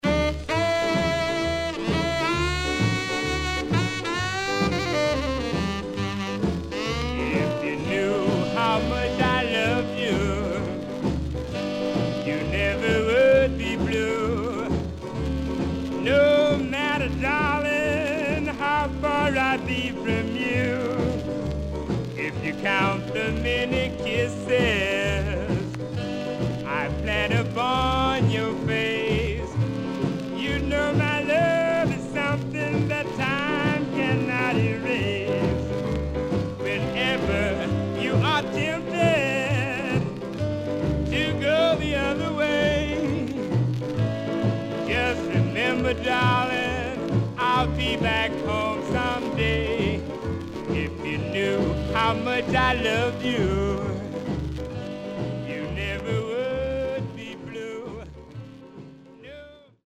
SIDE A:序盤に一発、少しチリノイズ入りますが良好です。